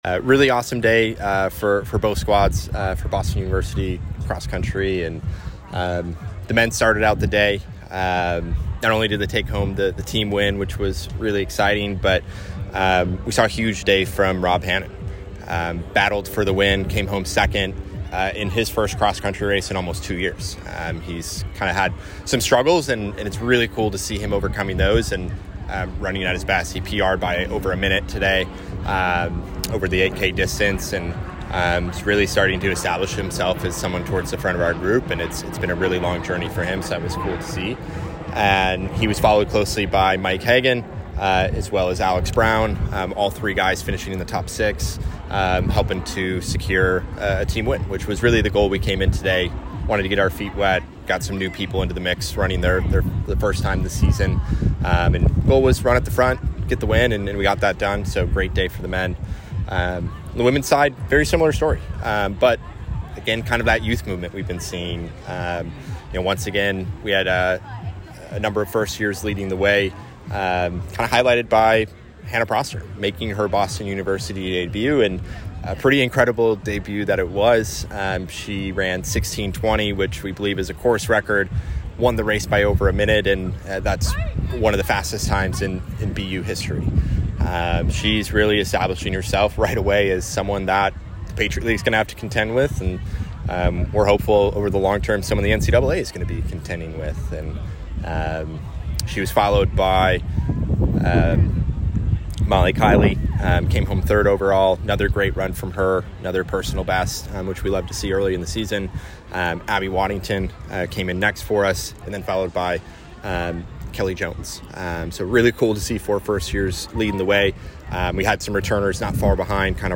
Cross Country / UMass Dartmouth Invitational Postrace Interview